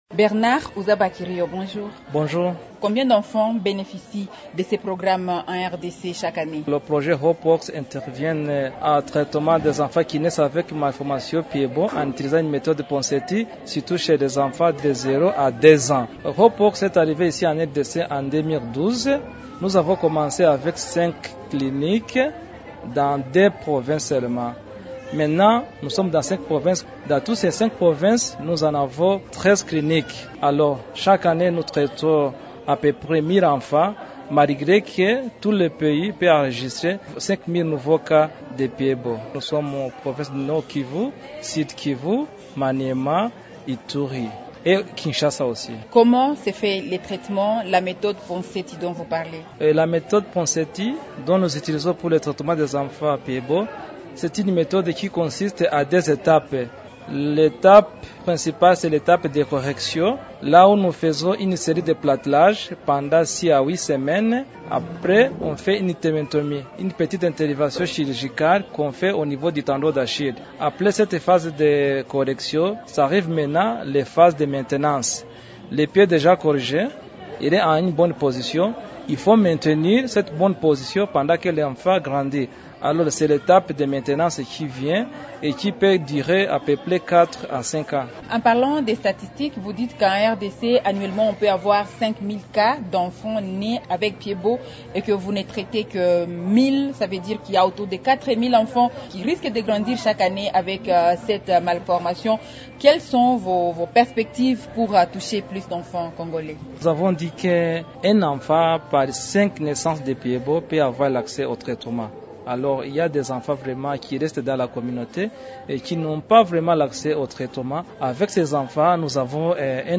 Dans une interview